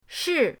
shi4.mp3